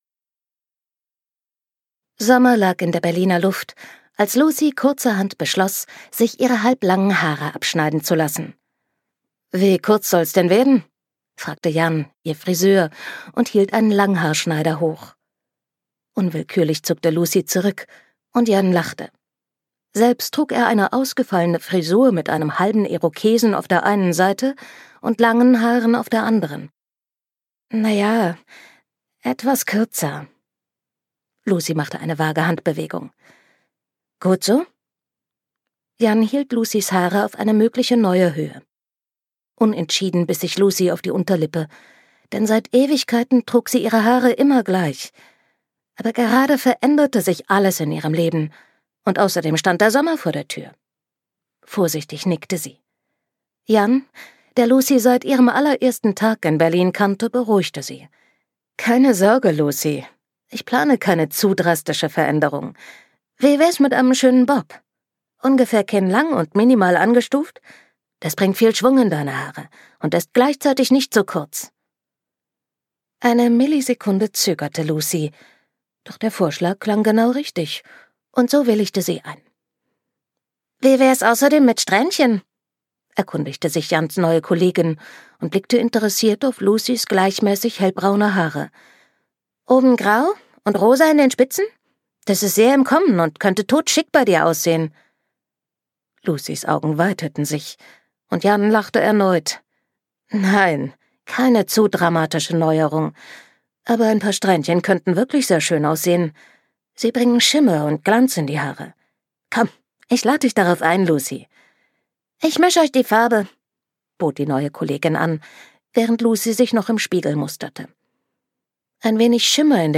Die Liebe wohnt im zweiten Stock links - Anna Herzblum | argon hörbuch
Gekürzt Autorisierte, d.h. von Autor:innen und / oder Verlagen freigegebene, bearbeitete Fassung.